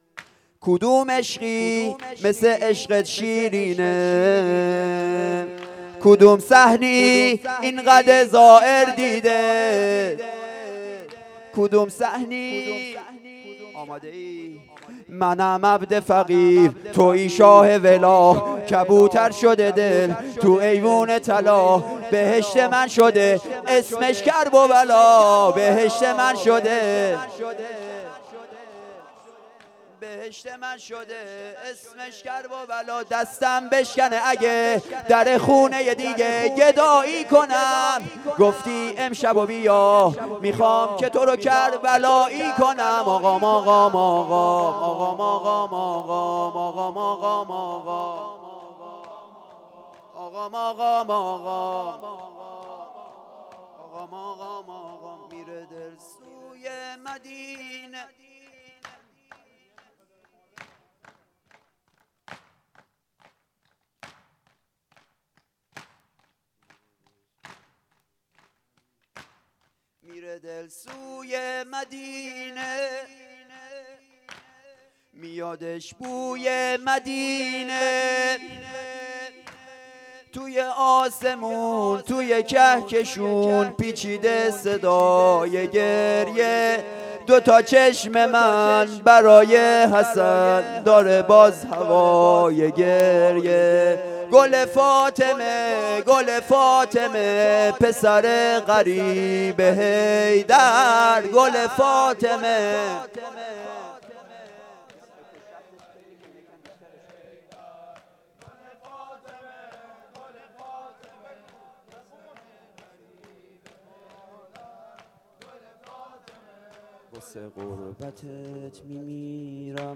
شور - سه ضرب
مراسم هفتگی